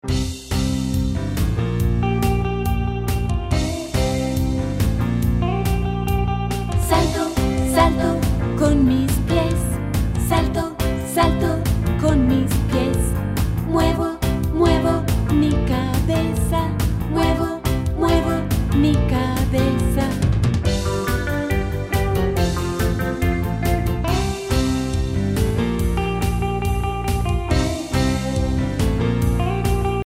A Movement Song for Learning Spanish